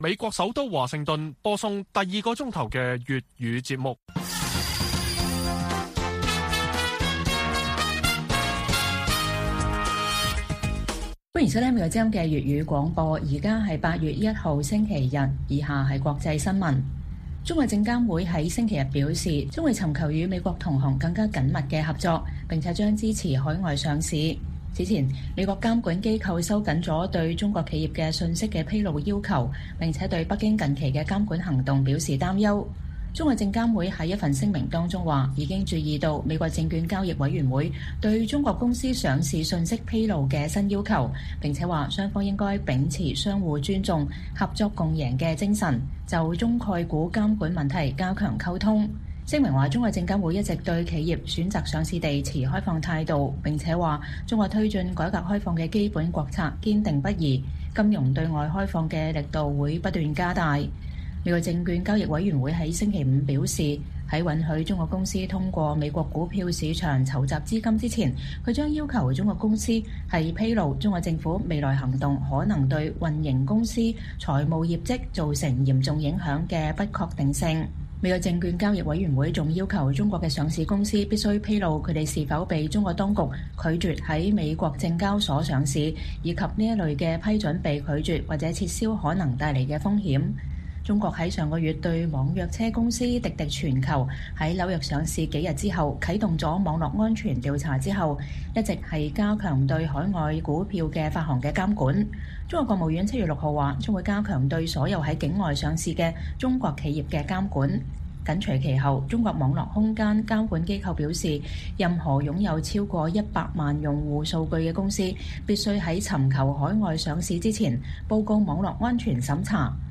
粵語新聞 晚上10-11點: 中國證監機構尋求與美進一步合作